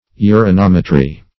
Search Result for " urinometry" : The Collaborative International Dictionary of English v.0.48: Urinometry \U`ri*nom"e*try\, n. The estimation of the specific gravity of urine by the urinometer.